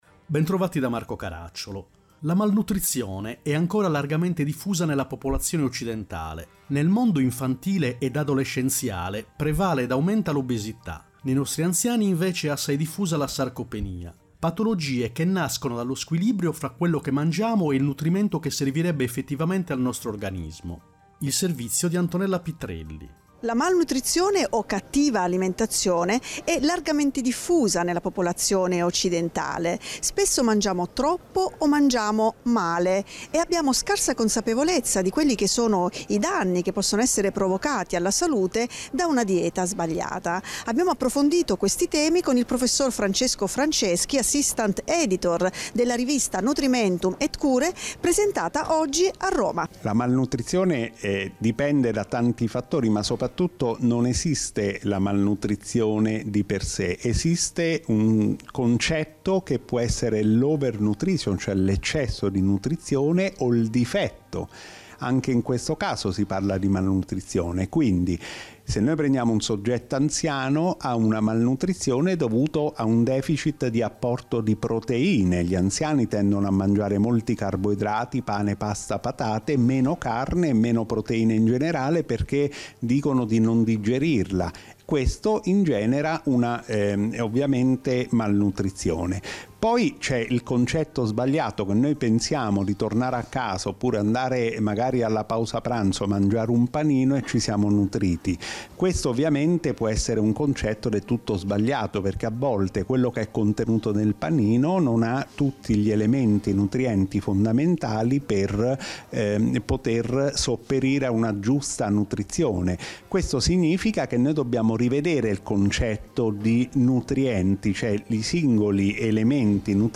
Radio
Puntata con sigla